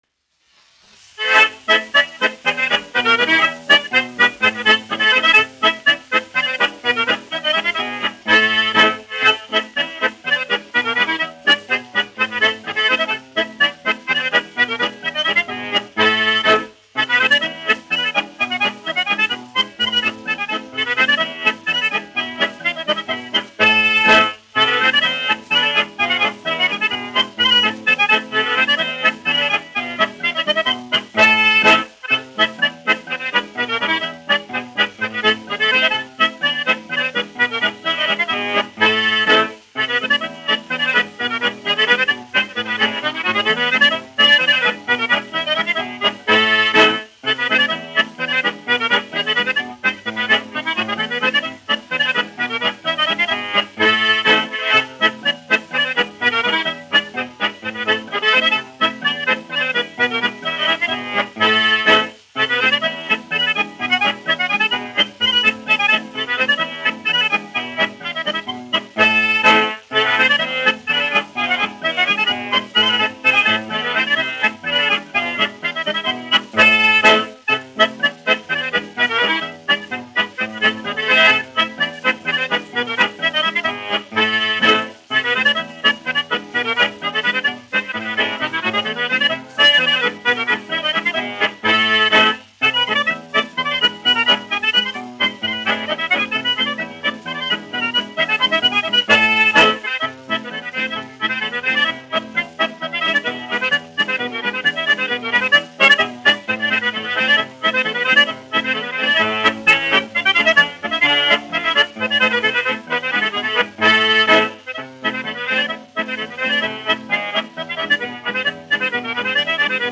1 skpl. : analogs, 78 apgr/min, mono ; 25 cm
Polkas
Populārā instrumentālā mūzika
Ermoņiku solo
Skaņuplate